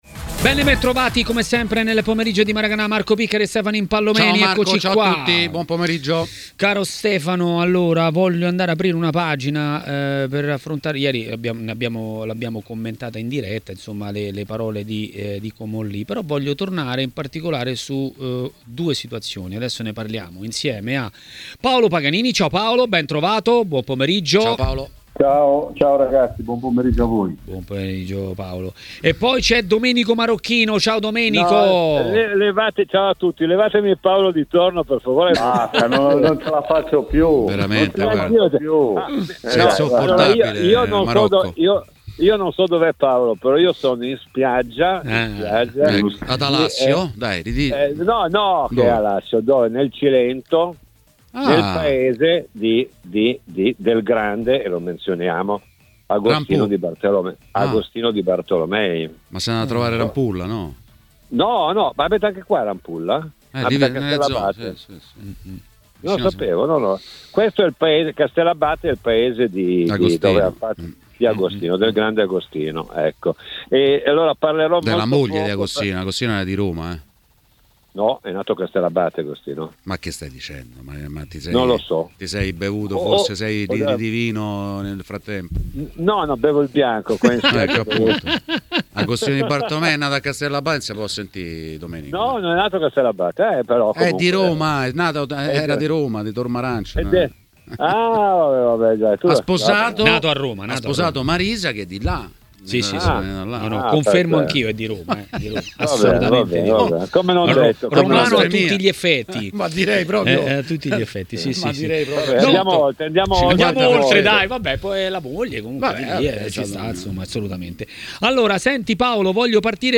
A parlare di Juventus a Maracanà, nel pomeriggio di TMW Radio, è stato Domenico Marocchino: "Vlahovic?